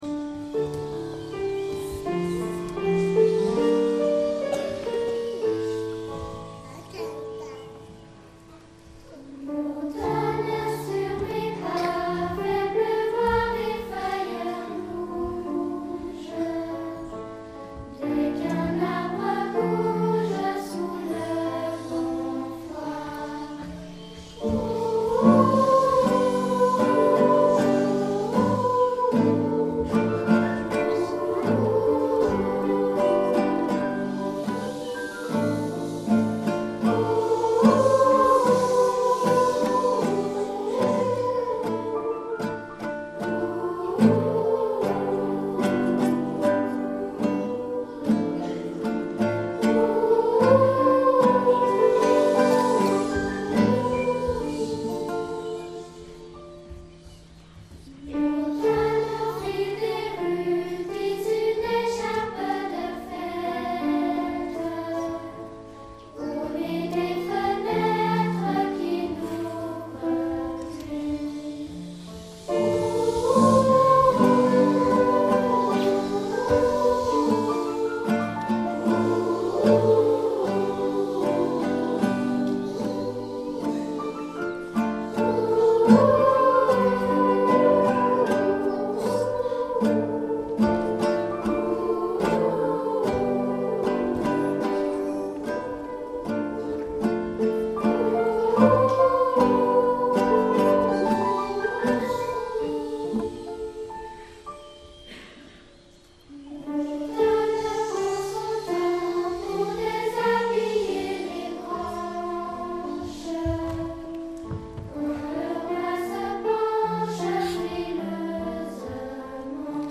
Spectacle "On part en auto" - Choeur d'enfants La Voix du Gibloux